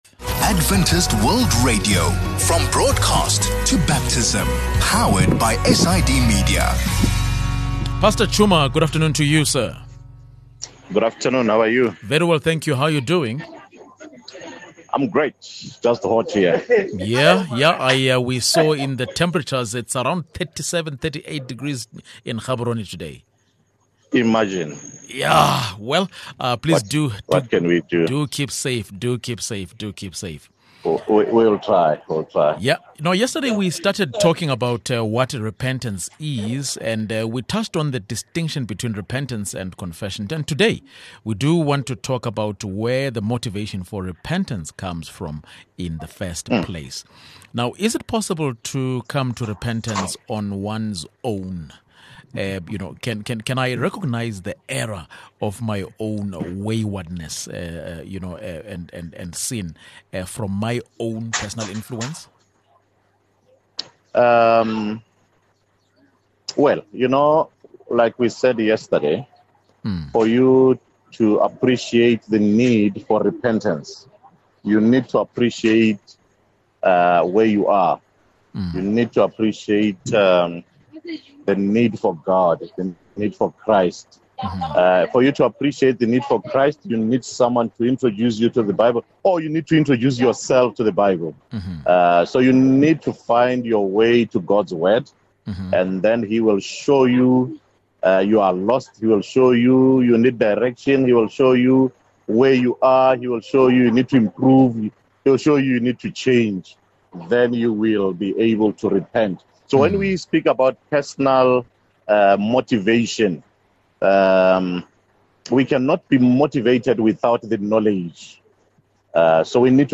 In this conversation, we discuss where the motivation for repentance comes from.